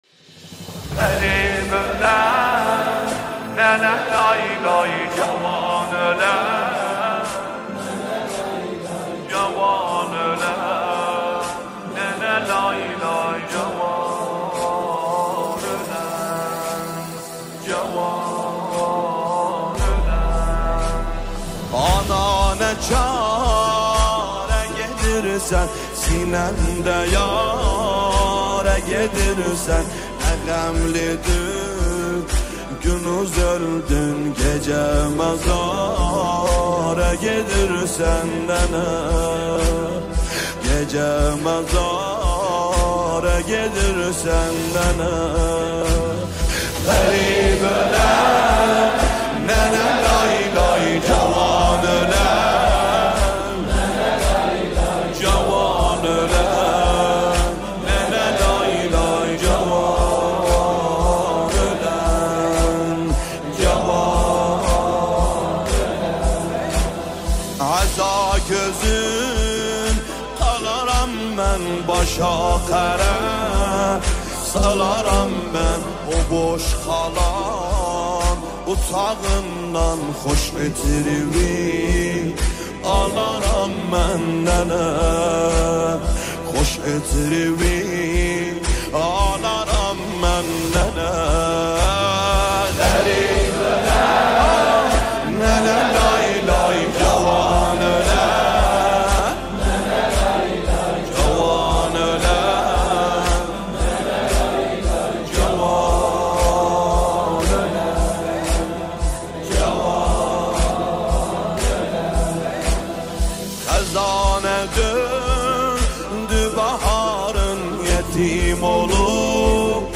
مداحی شور | جوان اولن ننه لایلای
شور احساسی
یکی از آثار شنیدنی و پرشور مذهبی است که با حال و هوای معنوی و سوزناک، دل‌های عاشقان اهل بیت را به خود جذب کرده است.